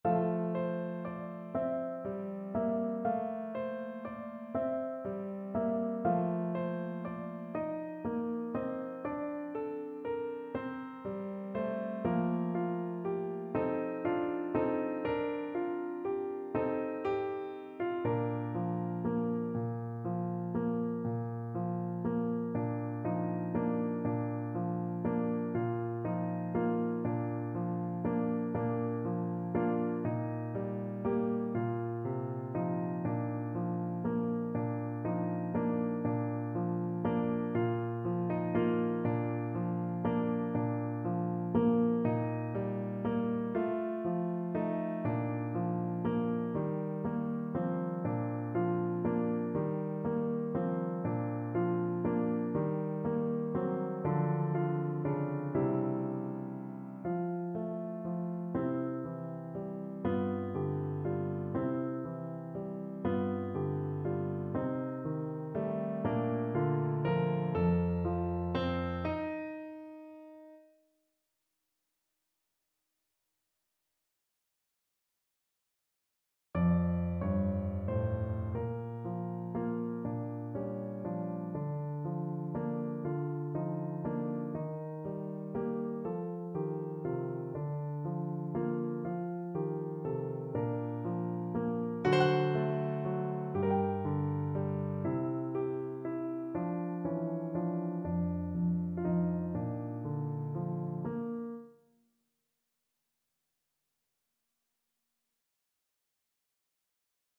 Moniuszko: Aria Halki (na flet i fortepiany)
Symulacja akompaniamentu